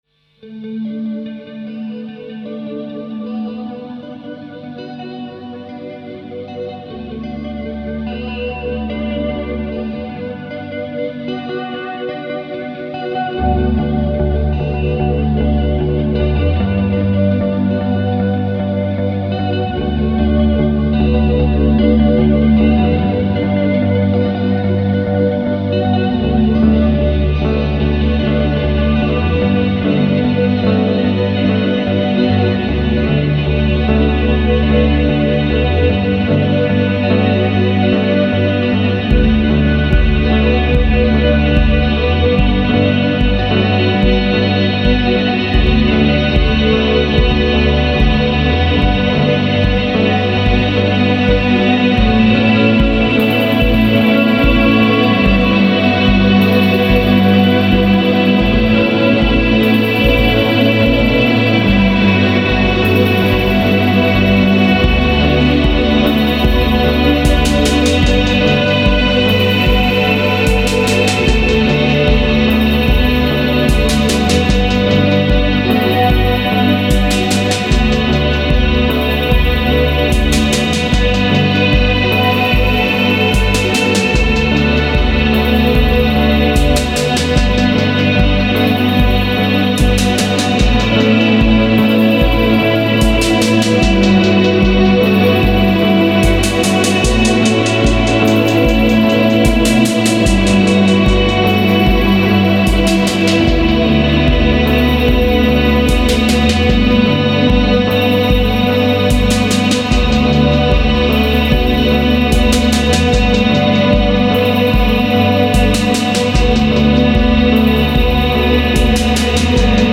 Жанр: Alternative / Punk.